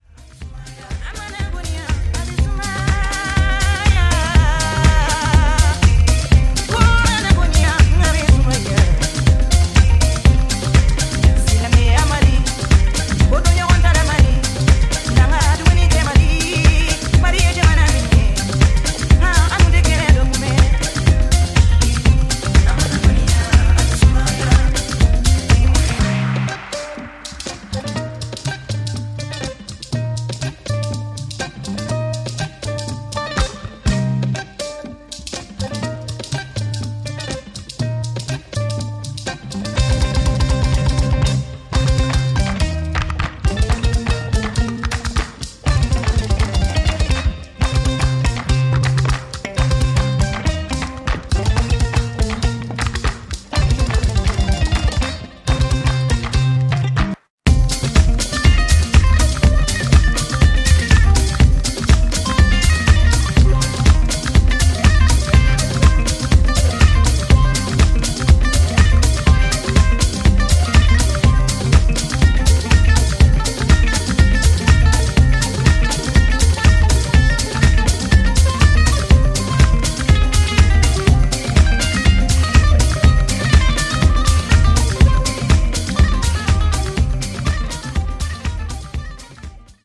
アフリカン・ブギー、ハイライフ、ズーク路線の楽曲をDJユースに捌いたディスコハウスを全4曲を披露しています！